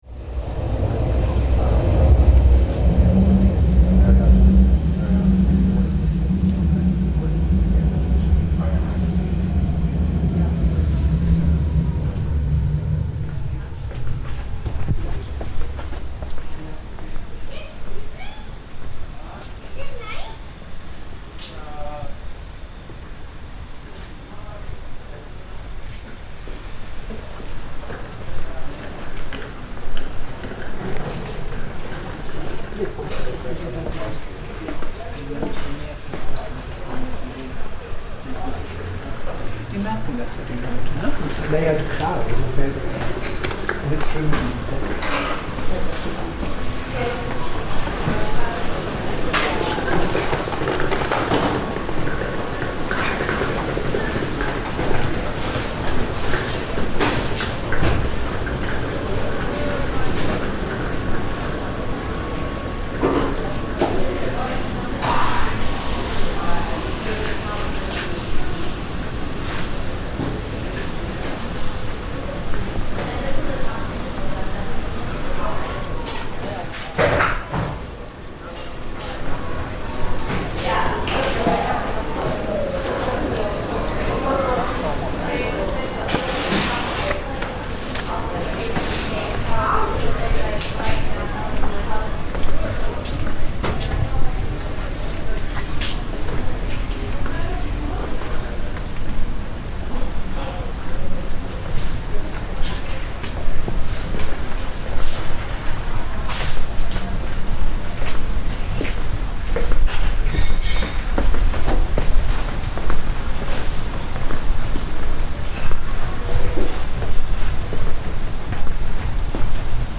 Inside the Bücherbogen bookshop in Savignyplatz, a fantastic bookshop of art, design and architecture books built under a set of railway arches. A unique soundscape of the quiet of a bookshop, with the flicking of pages, with the deep rumble overhead of periodic passing trains.